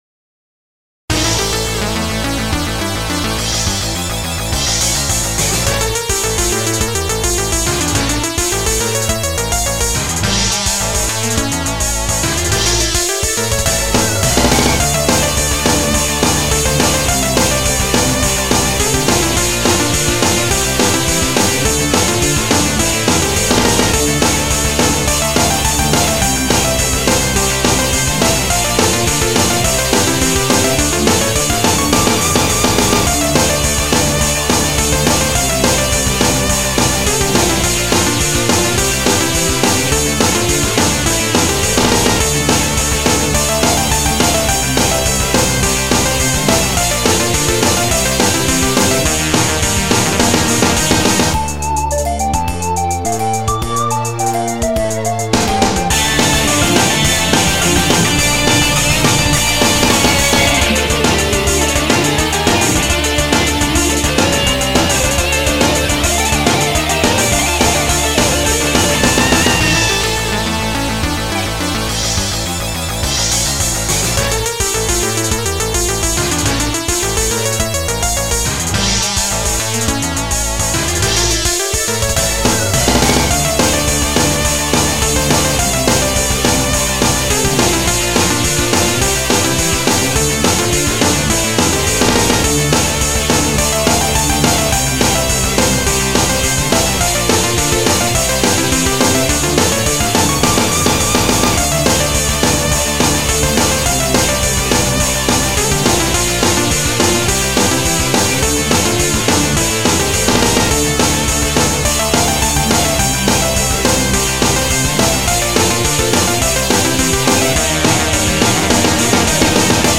ゲーム音楽アレンジ集